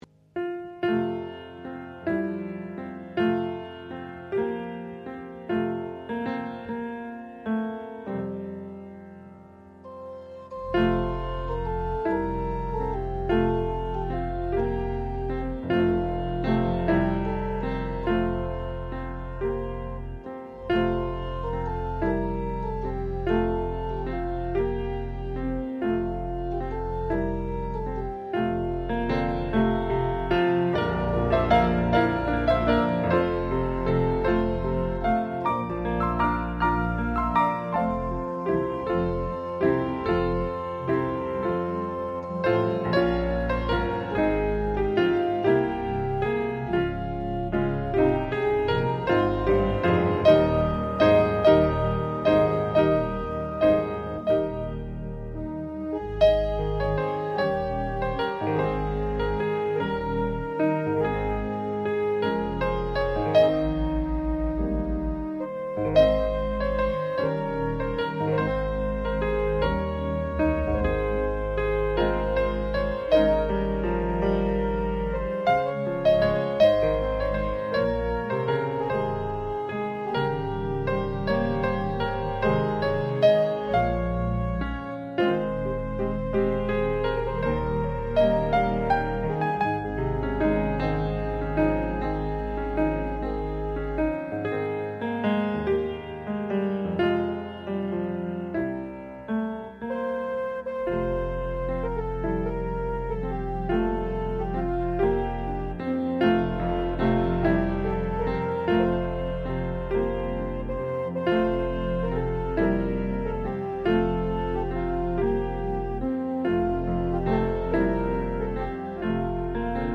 evening worship of Christmas Music